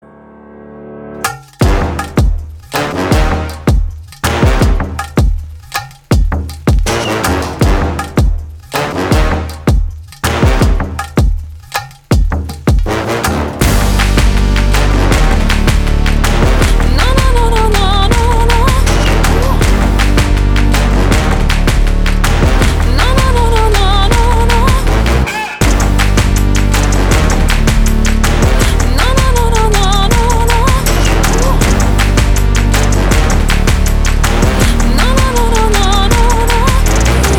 • Качество: 320, Stereo
женский голос
мощные басы
Trap
Громкий и стильный рингтон